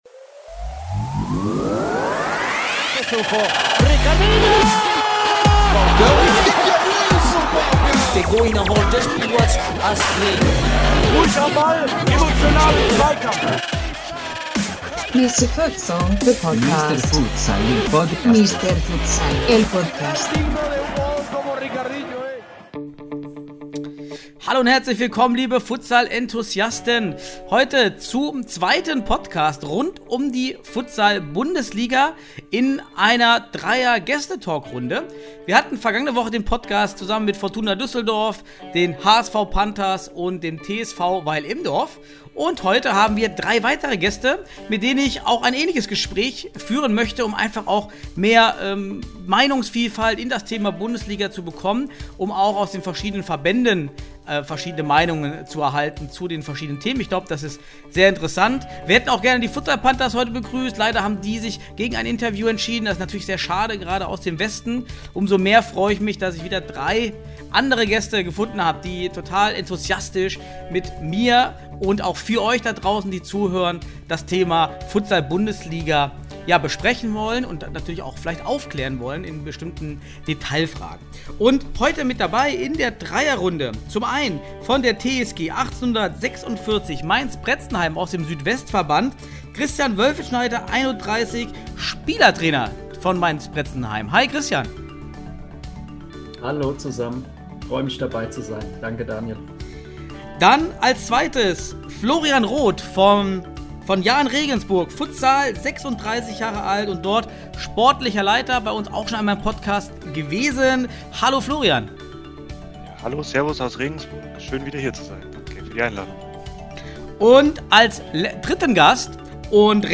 #77: Bundesliga-Talk 2.0: Jahn Regensburg, Mainz & Stuttgart ~ Futsal Podcast
Aufgrund des großen Bundesliga-Interesses kurz vor Ende der Lizenzierungs-Frist am 1. April, haben wir uns für eine zweite Bundesliga-Talkrunde mit drei potenziellen Bundesliga-Qualifikanten entschieden.